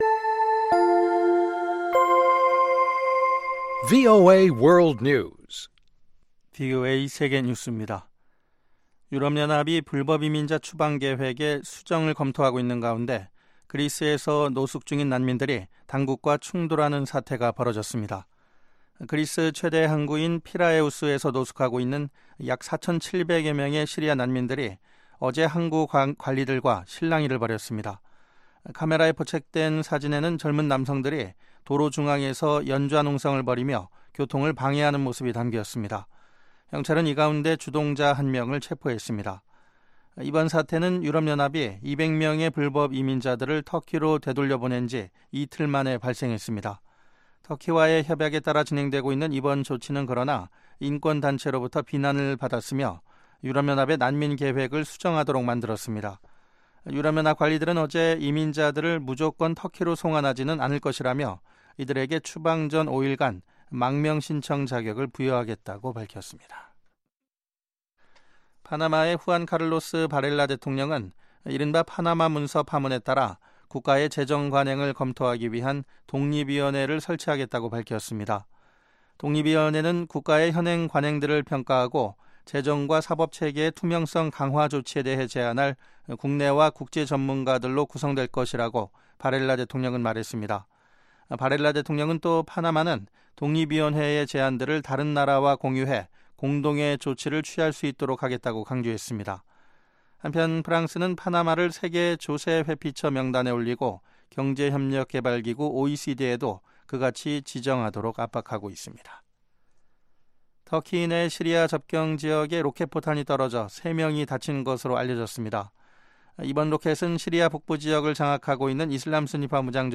VOA 한국어 방송의 간판 뉴스 프로그램 '뉴스 투데이' 2부입니다. 한반도 시간 매일 오후 9:00 부터 10:00 까지, 평양시 오후 8:30 부터 9:30 까지 방송됩니다.